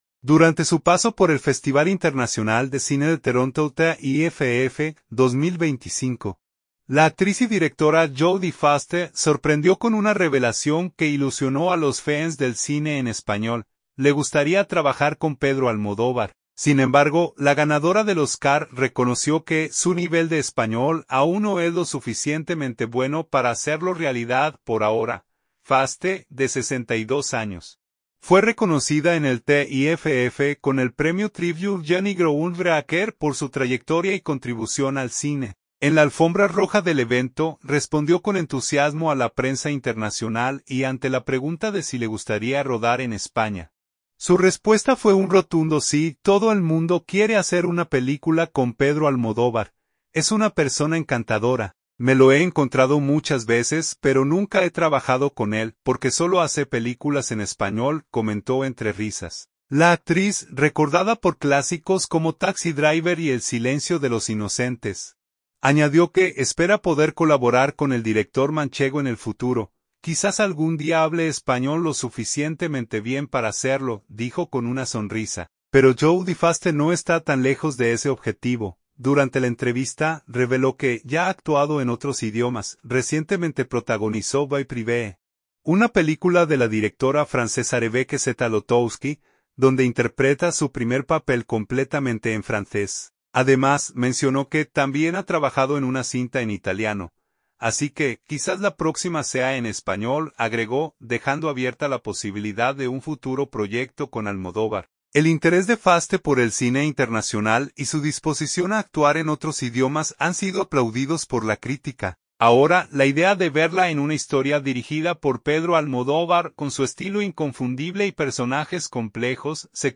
En la alfombra roja del evento, respondió con entusiasmo a la prensa internacional, y ante la pregunta de si le gustaría rodar en España, su respuesta fue un rotundo “¡Sí!”.
“Todo el mundo quiere hacer una película con Pedro Almodóvar. Es una persona encantadora. Me lo he encontrado muchas veces, pero nunca he trabajado con él… ¡porque solo hace películas en español!”, comentó entre risas.